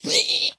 zpighurt2.mp3